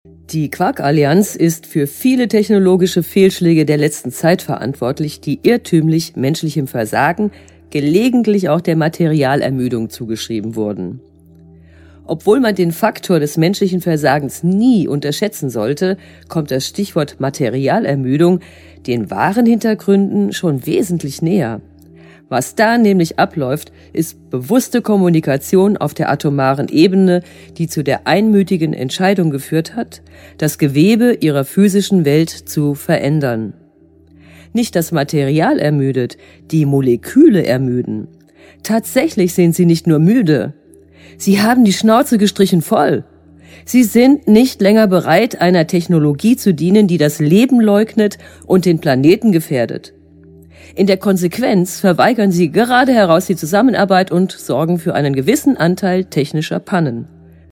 Deutsche Sprecherin Charakteristische, temperamentvolle, expressive und gefühlvolle Stimme Mittlere Stimmlage Stimme auf der CD Katzensehnsucht und CD E.T. 101 DAS KOSMISCHE HANDBUCH ZUR PLANETAREN (R)EVOLUTION
Sprechprobe: Werbung (Muttersprache):